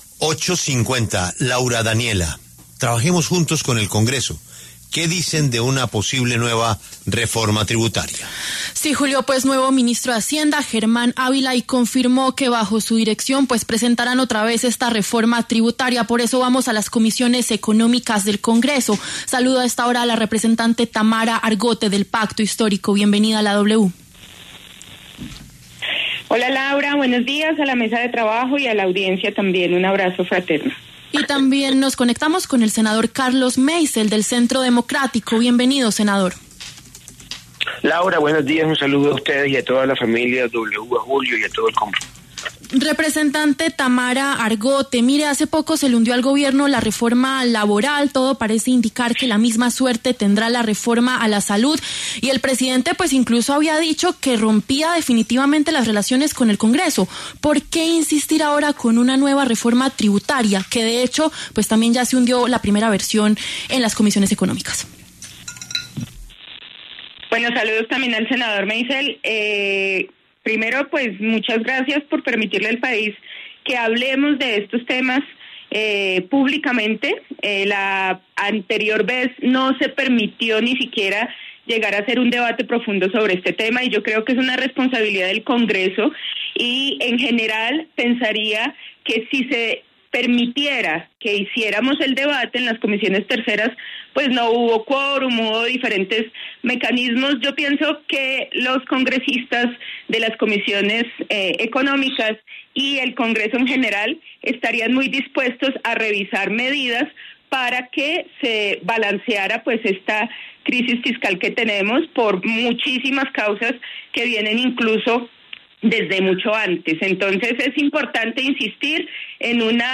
Los congresistas de las comisiones económicas, Tamara Argote, del Pacto Histórico, y Carlos Meisel, del Centro Democrático, debatieron en La W.